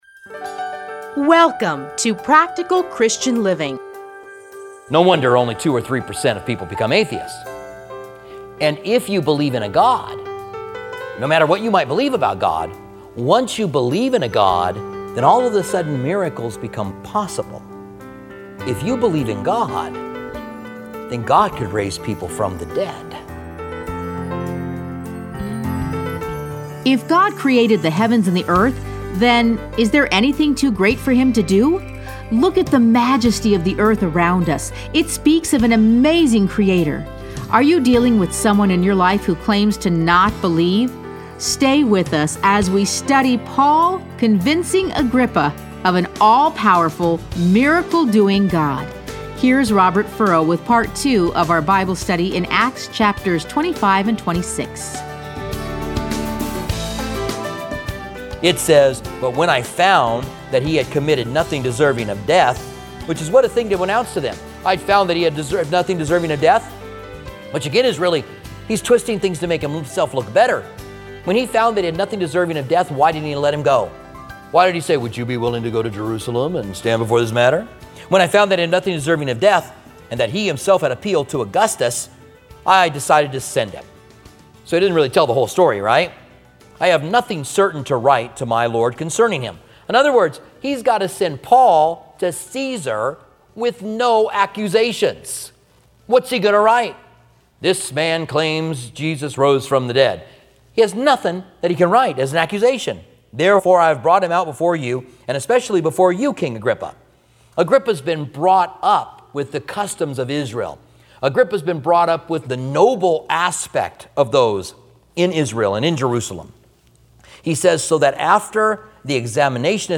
Listen to a teaching from Acts 25-26.